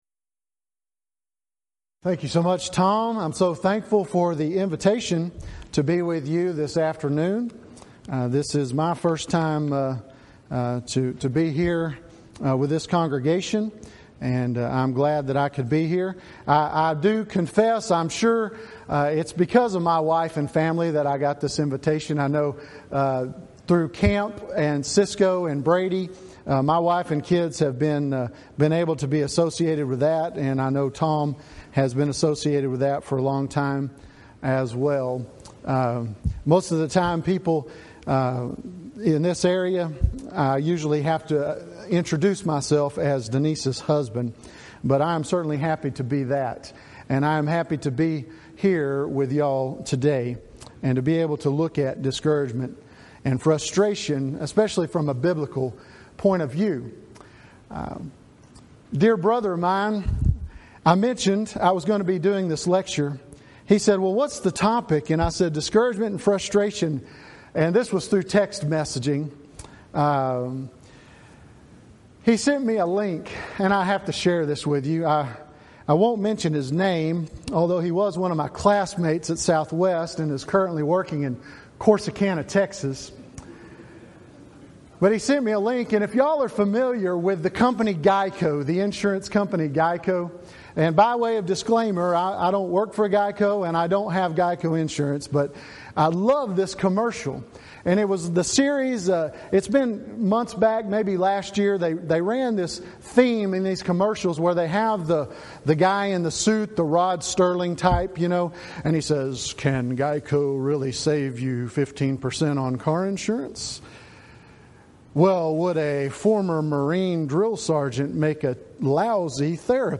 6th Annual Back to the Bible Lectures